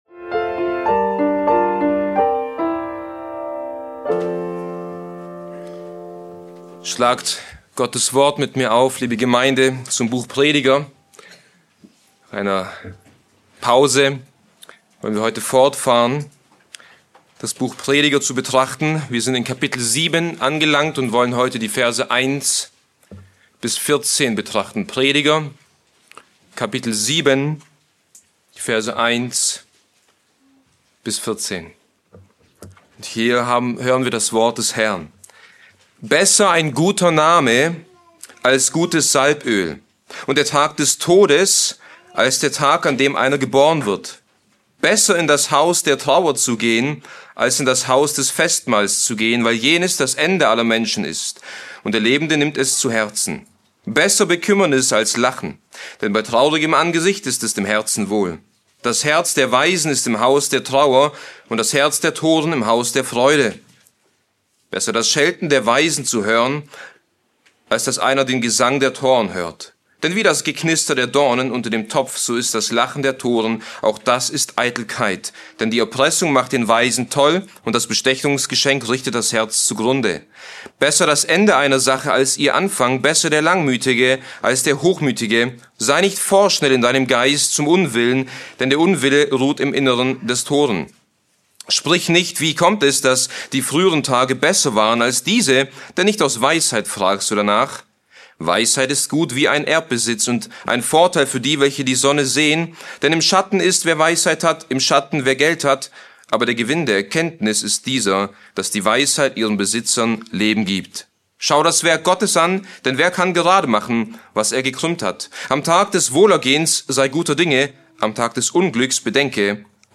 Diese Predigt über Prediger 7,1-14 behandelt fünf wichtige Lektionen aus der "Schule des Lebens", die Gott uns lehren möchte: Der Prediger erklärt, dass Beerdigungen und Zeiten der Trauer lehrreicher sind als oberflächliche Feiern, da sie uns mit der Vergänglichkeit des Lebens konfrontieren...